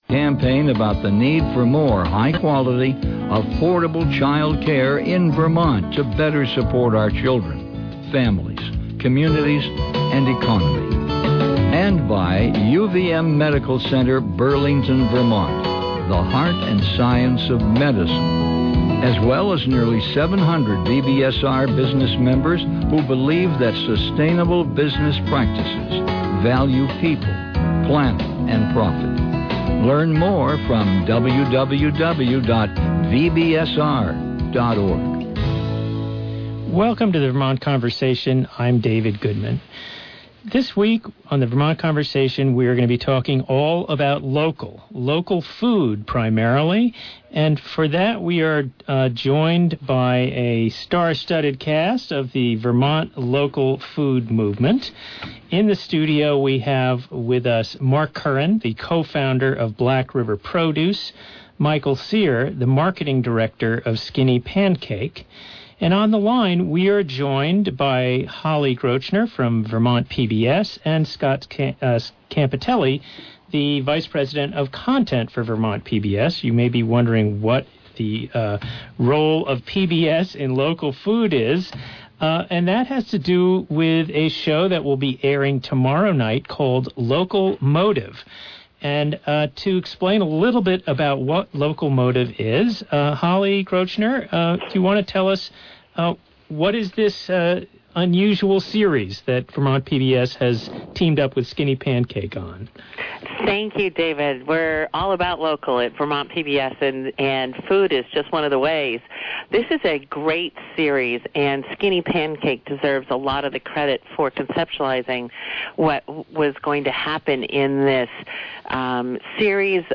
We discuss the history and future of the local food movement, and a new documentary series on Vermont PBS, The Local Motive. (December 6, 2017 broadcast)